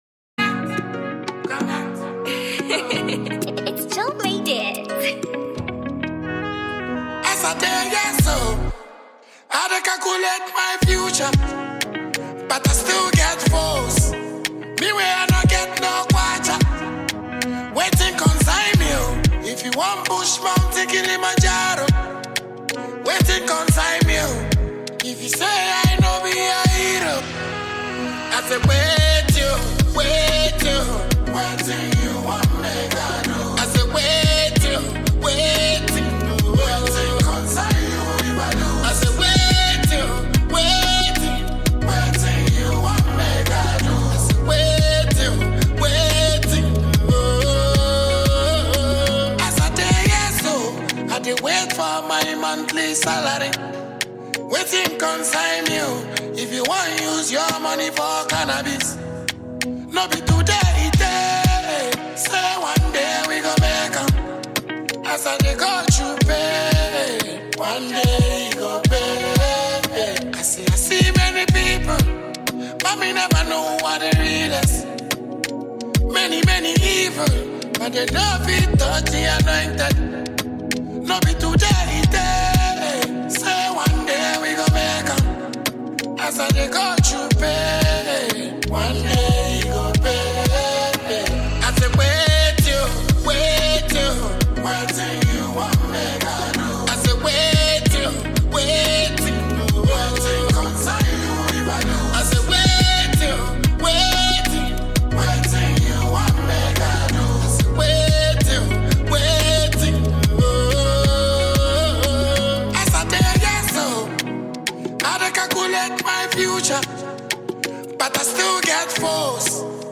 multiple award-winning Ghanaian dancehall musician